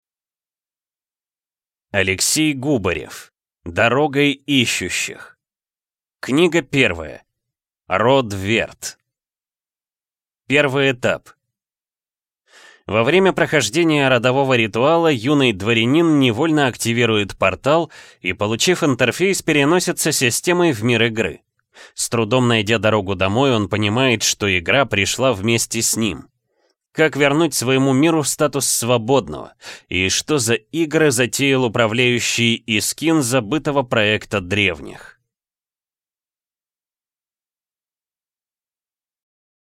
Аудиокнига Род Верд. Книга 1 | Библиотека аудиокниг